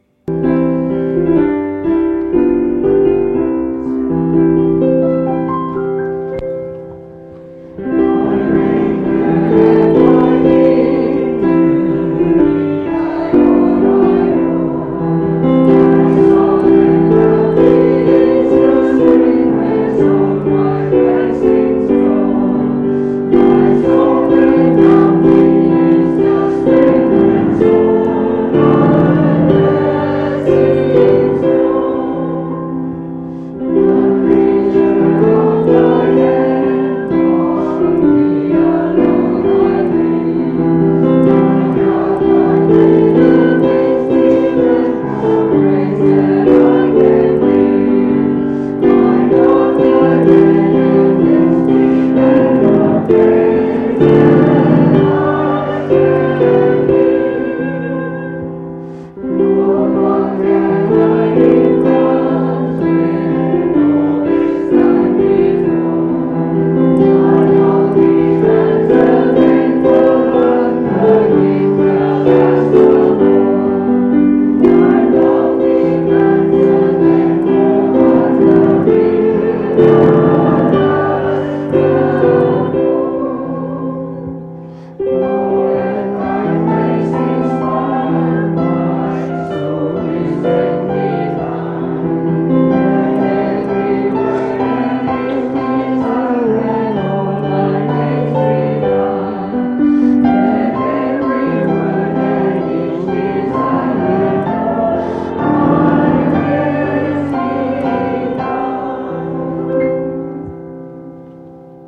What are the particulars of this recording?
Sabbath Sermons 2020